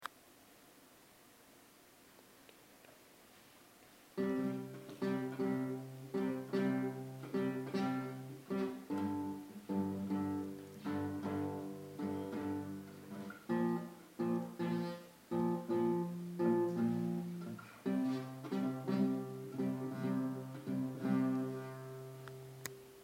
- Compás: 6/8.
- Tonalidad: Do menor
Guitarra